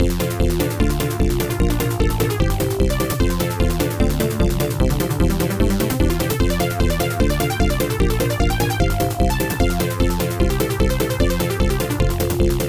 Surprise! AdLib Tracker
Snare1 Snare2 Open-Hi CHORD4 FULL LEAD